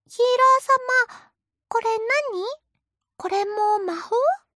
子供 Ver.
CV：能登麻美子